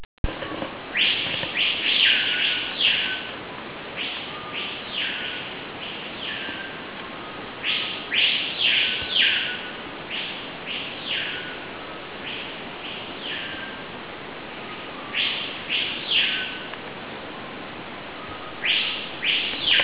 Suoni della foresta:
il verso di alcuni uccelli
jungle.wav